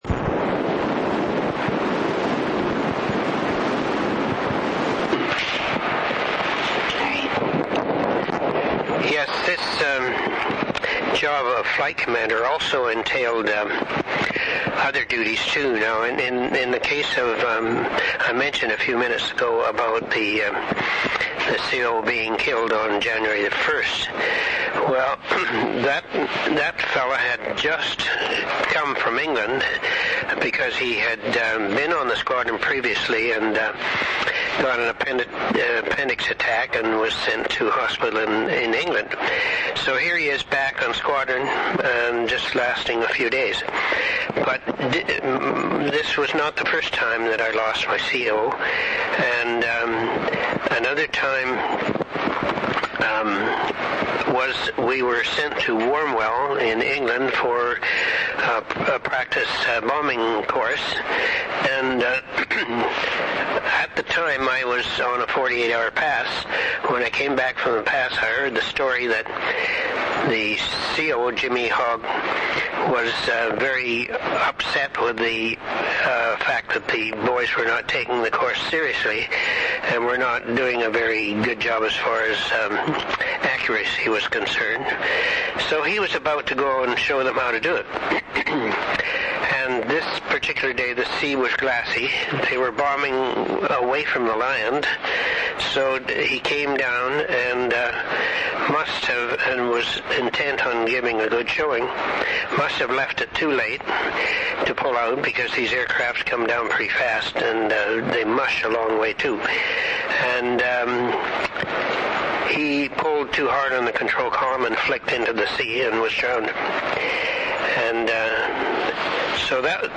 Interview took place on February 17, 2005.